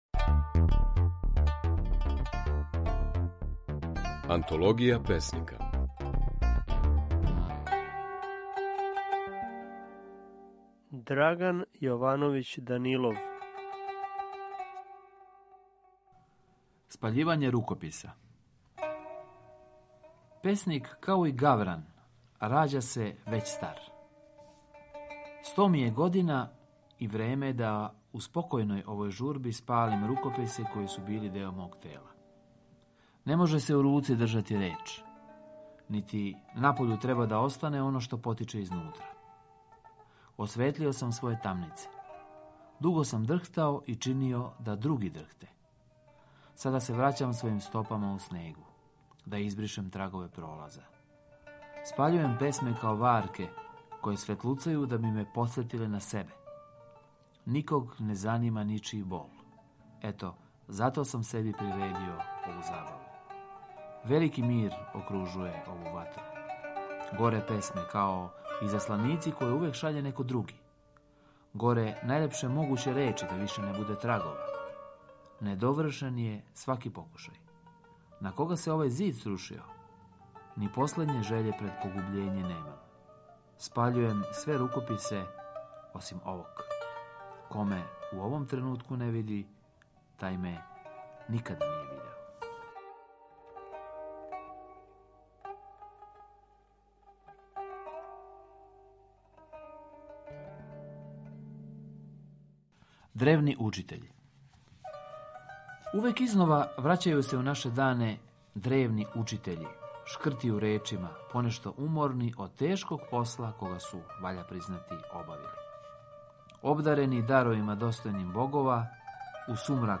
Можете чути како своје стихове говори песник Драган Јовановић Данилов.
Емитујемо снимке на којима своје стихове говоре наши познати песници